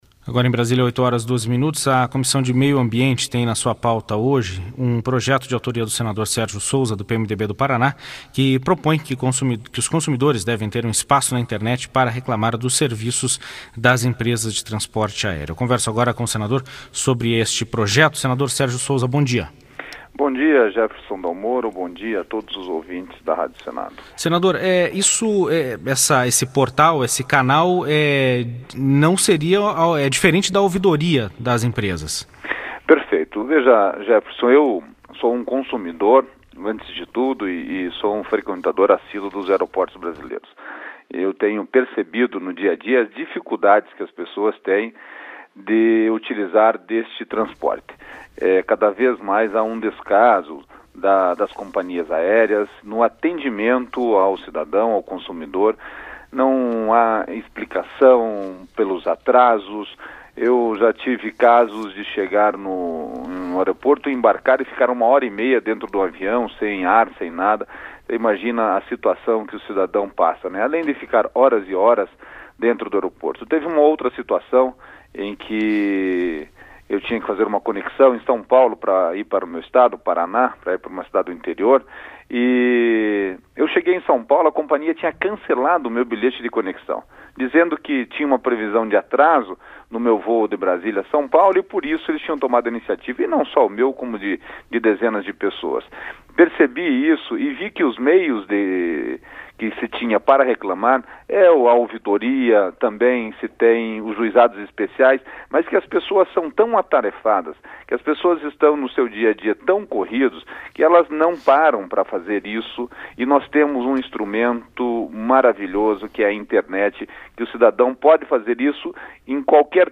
Entrevista com o senador Sérgio Souza (PMDB-PR).